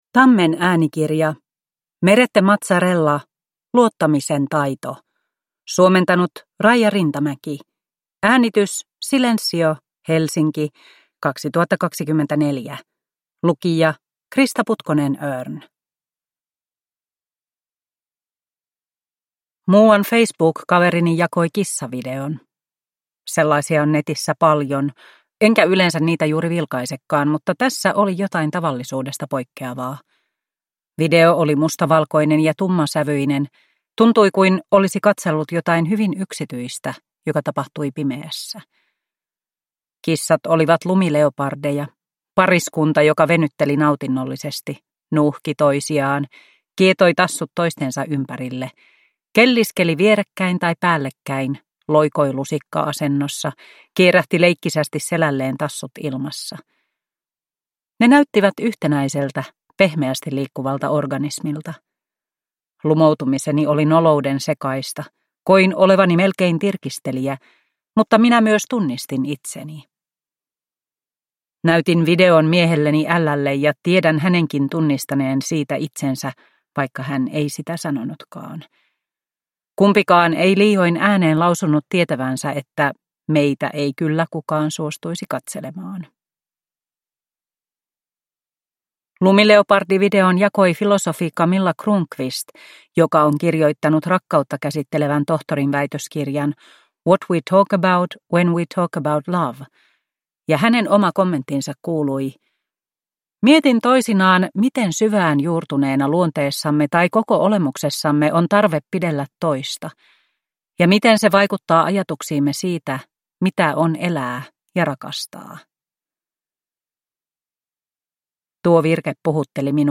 Luottamisen taito (ljudbok) av Merete Mazzarella